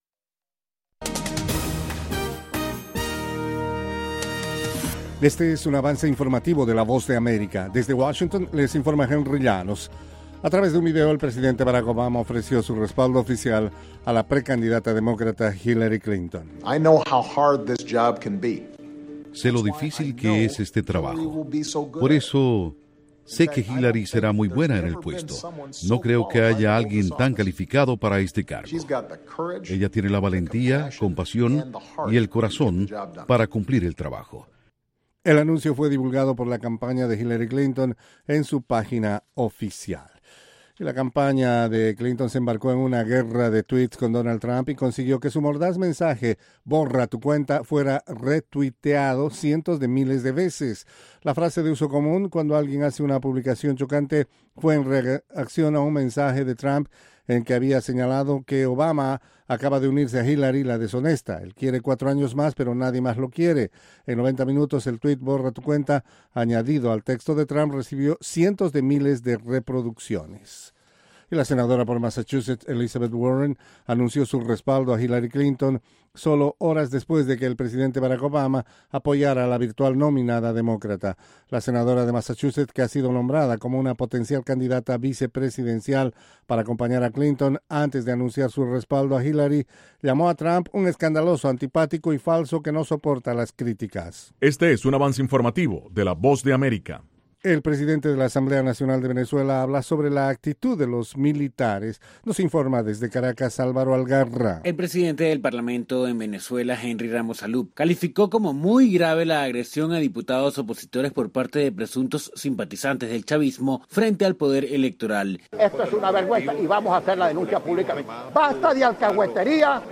Capsula informativa de tres minutos con el acontecer noticioso de Estados Unidos y el mundo.